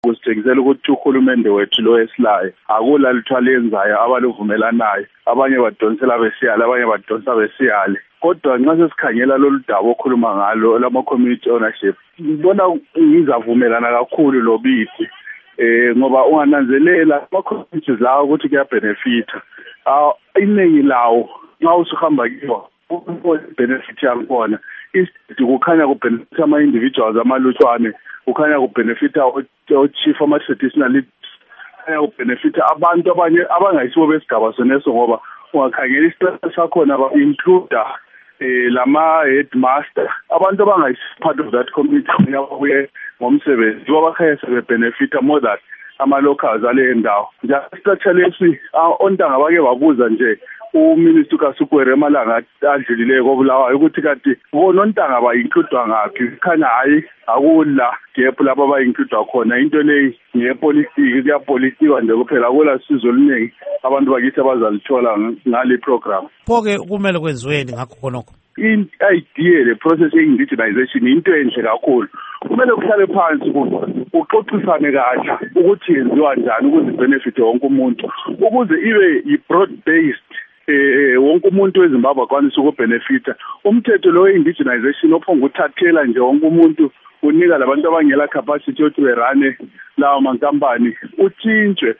Ingxoxo Esiyenze LoMnu.